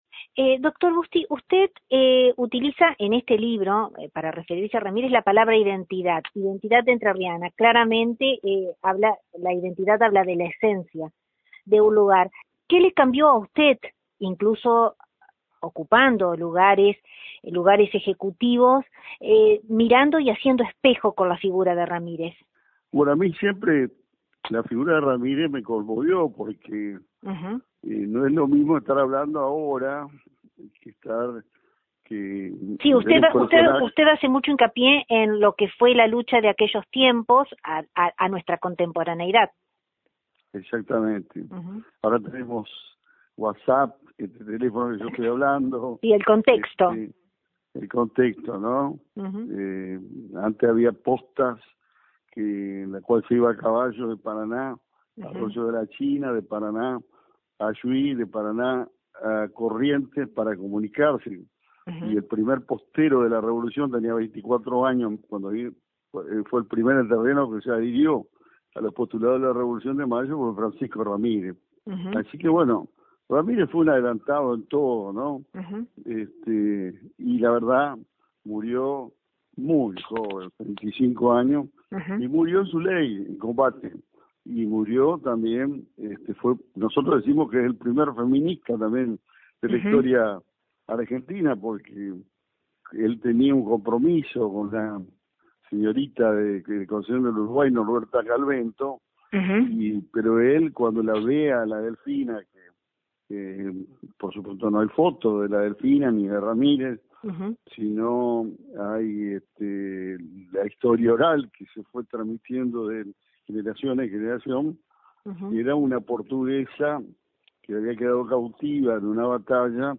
Desde LT39 NOTICIAS, dialogamos con el ex gobernador concordiense; con quien pudimos traer al presente, la figura relevante del ex caudillo Pancho Ramírez, siembra fiel de grandes ideas instaladas y cosechadas a lo largo de nuestro transitar entrerriano; como así también, obtuvimos su apreciación de un presente complicado, que impera en nuestra realidad; donde no hizo mella al hacer un análisis político, atrevesado por nombres como el de Mauricio Macri y Eduardo Duhalde.